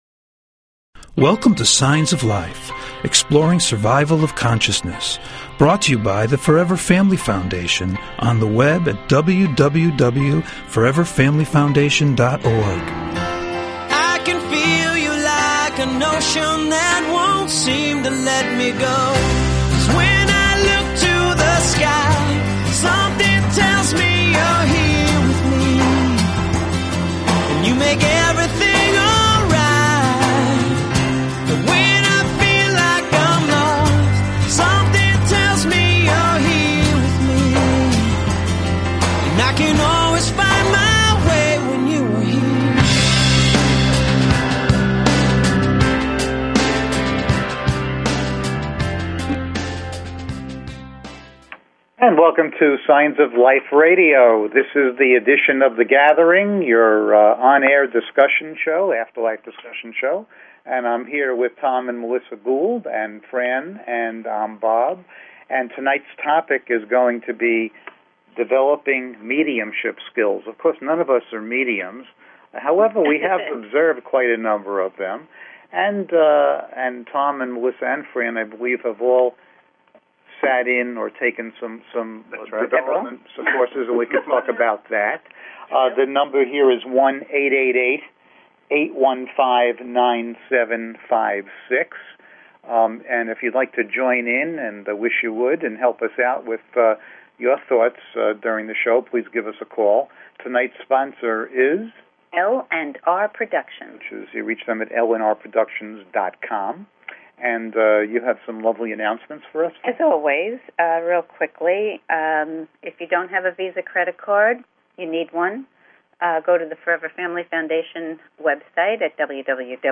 SHORT DESCRIPTION - - Afterlife Discussion Show - Topic: Developing Your Own Mediumship Skills
Call In or just listen to top Scientists, Mediums, and Researchers discuss their personal work in the field and answer your most perplexing questions.